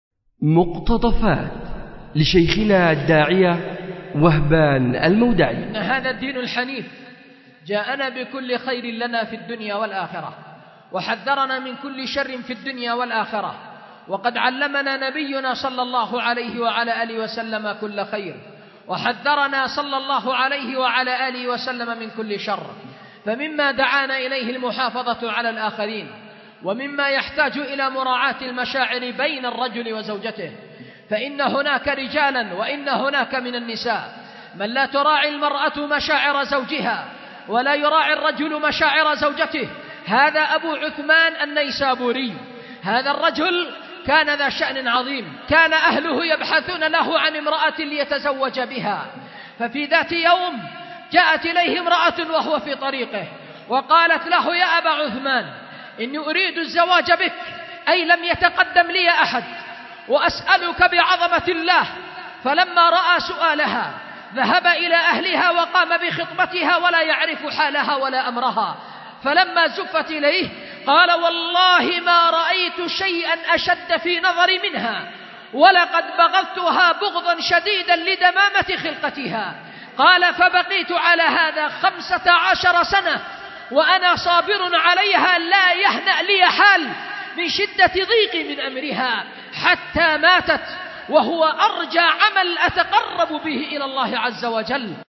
أُلقي بدار الحديث للعلوم الشرعية بمسجد ذي النورين ـ اليمن ـ ذمار 1444هـ